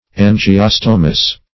Search Result for " angiostomous" : The Collaborative International Dictionary of English v.0.48: Angiostomous \An`gi*os"to*mous\, a. [Angio- + Gr.